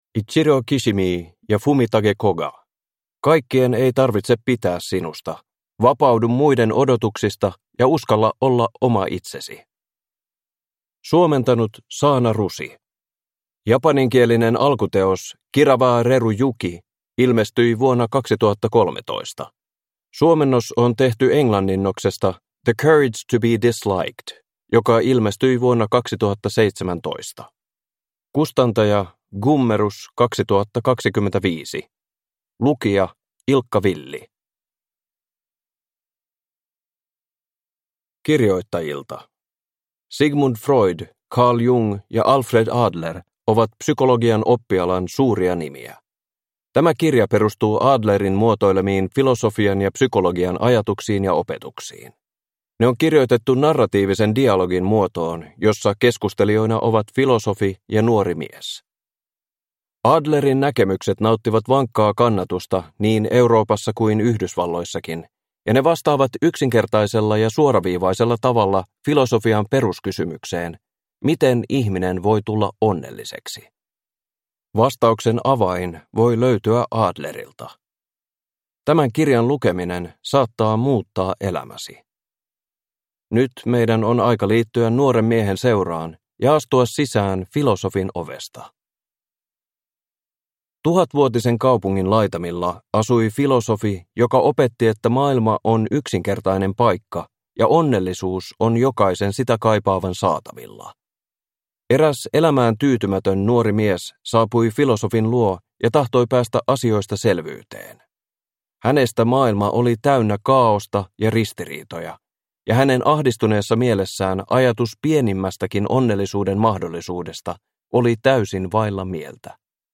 Kaikkien ei tarvitse pitää sinusta (ljudbok) av Ichiro Kishimi